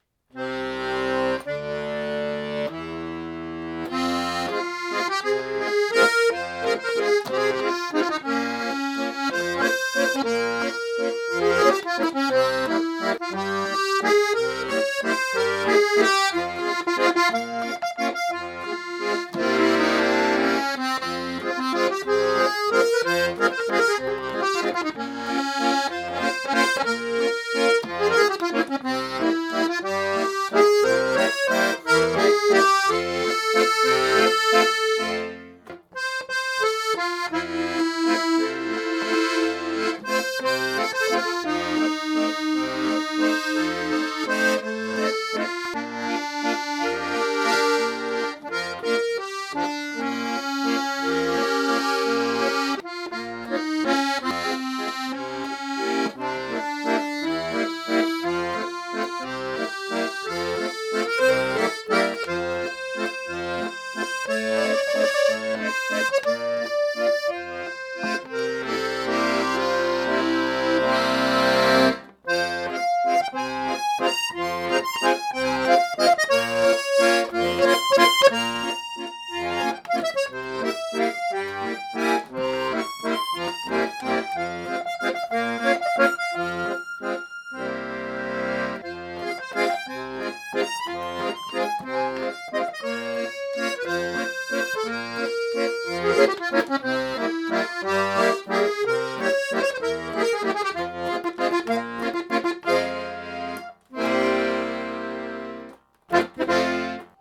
QUAGLIARDI, Italy (usata ) Knopf-Akkordeon C-Griff 5-Reihig gestuft tipo: Neu gestimmt 2021.
frequenza / tremolo die La=440/sec: 442 / 20 cent